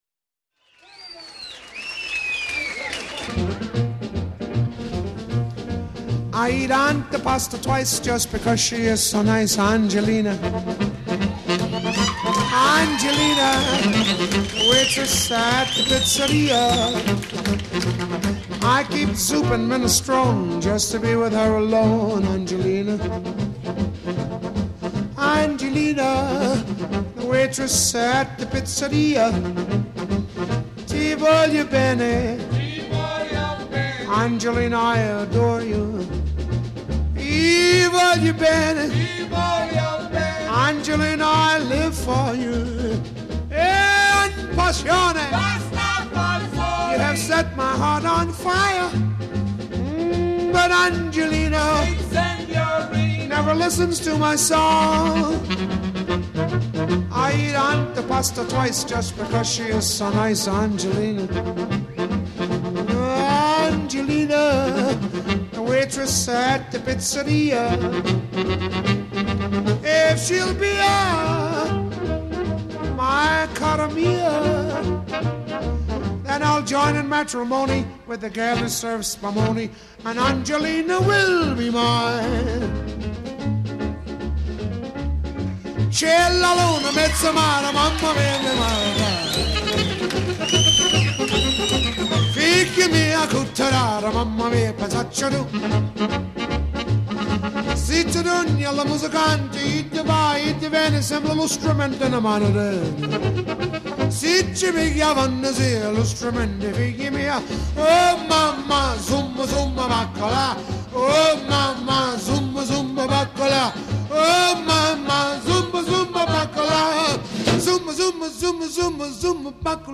musica italo-americana